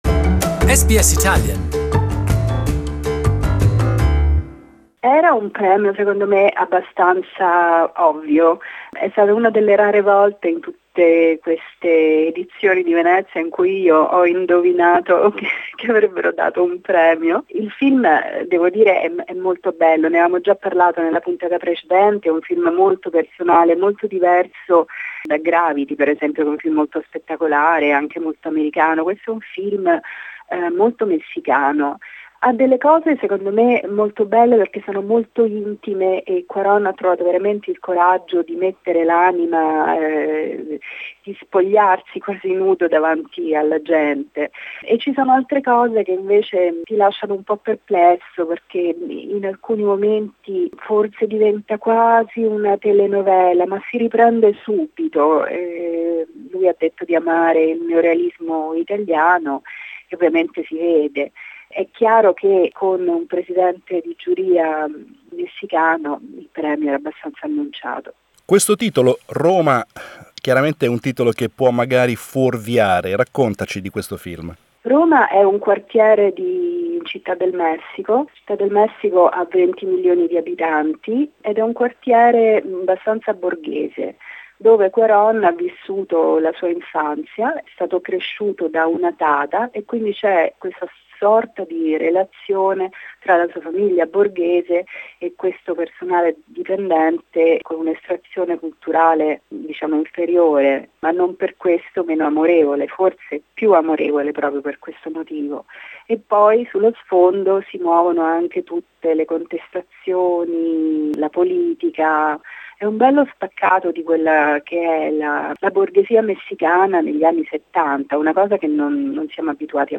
We analysed the result with our correspondent in Venice